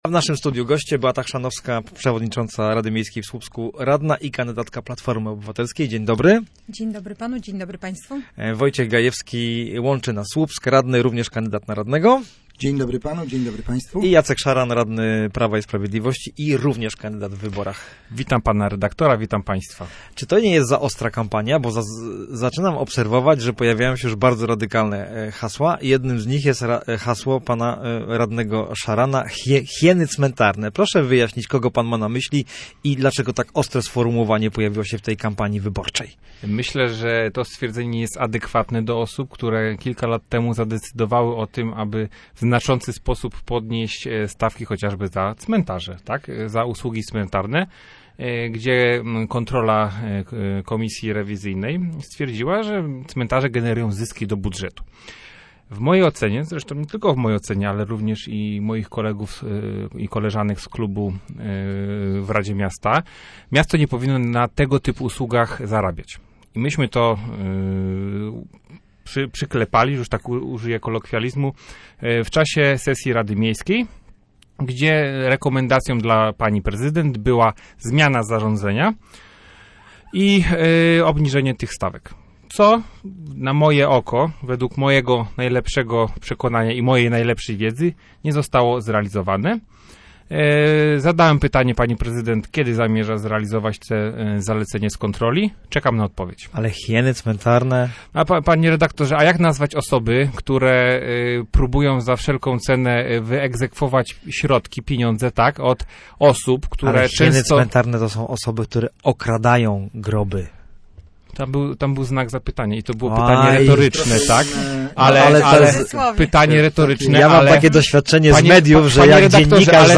Kampania w Słupsku nabiera tempa. Przedwyborcza dyskusja samorządowców